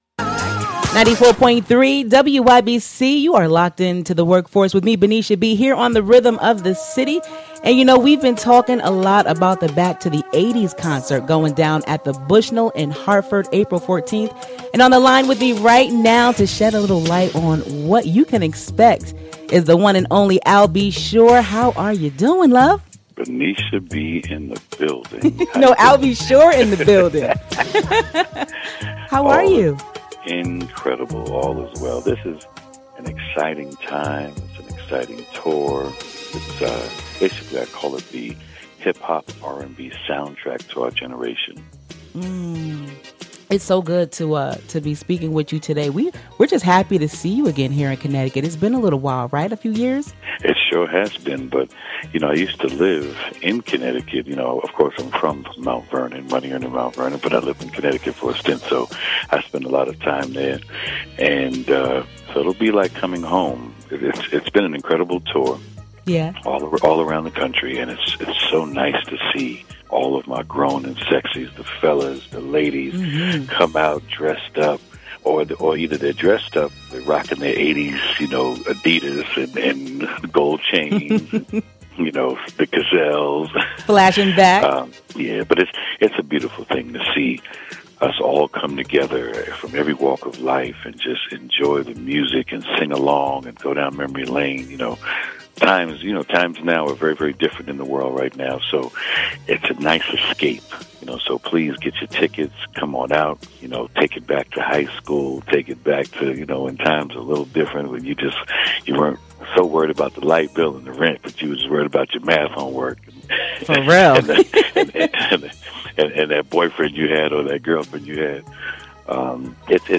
Workforce Interview with Al B Sure